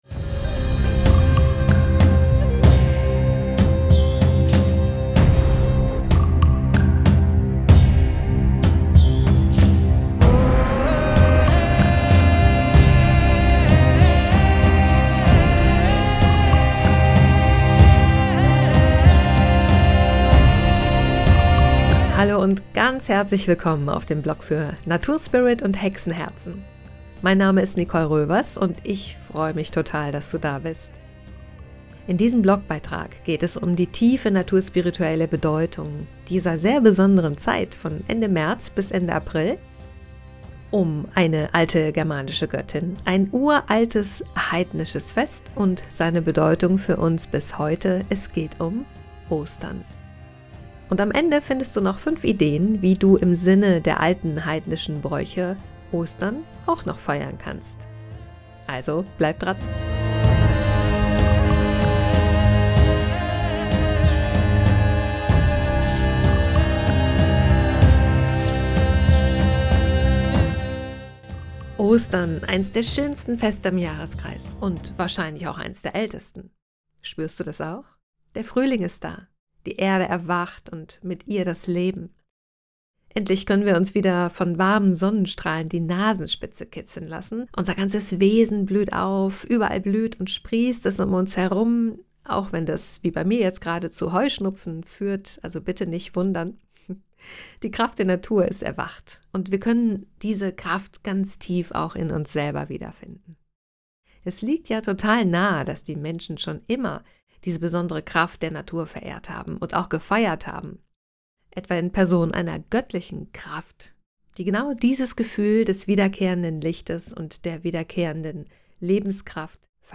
Du kannst diesen Beitrag auch anhören, denn ich habe ihn für dich eingesprochen.